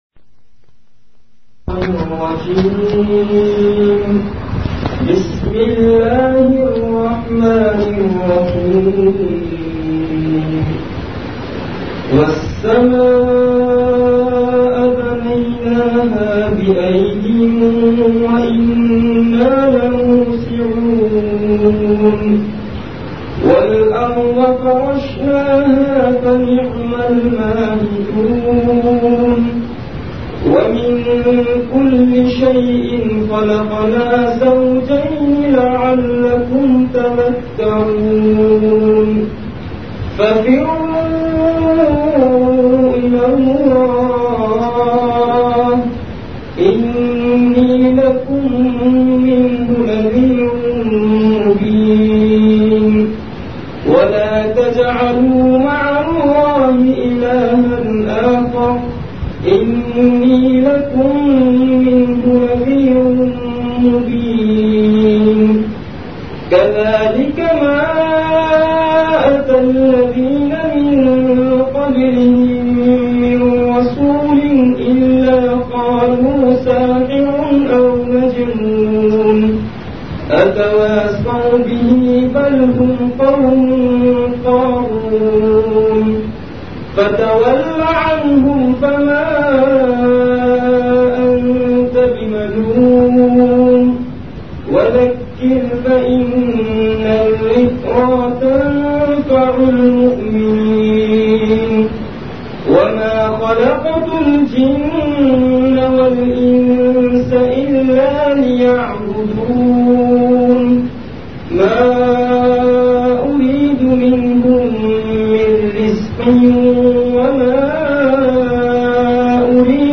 Delivered at Home.
Majlis-e-Zikr
After Isha Prayer